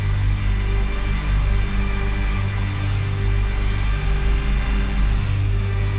DD_LoopDrone3-G.wav